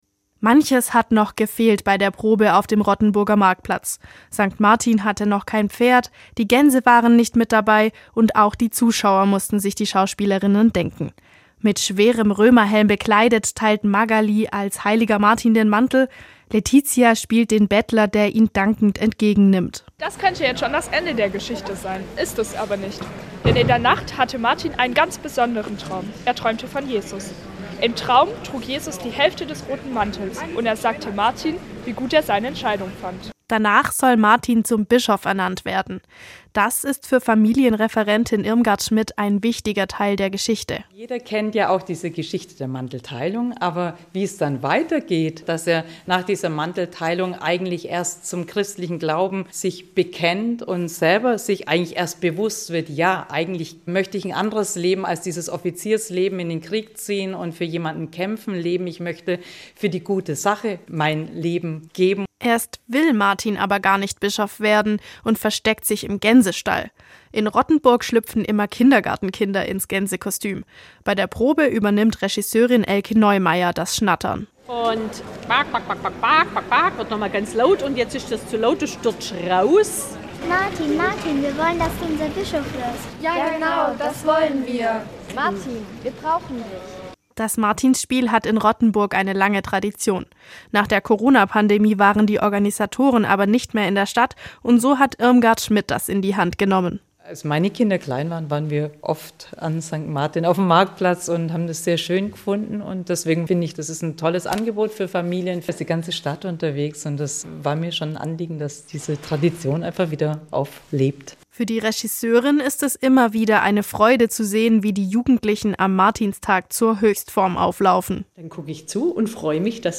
Manche Stimme klang noch zögerlich, am Martinstag werden die jungen Darstellerinnen dann mit einem Mikrofon ausgestattet und auf dem ganzen Marktplatz zu hören sein.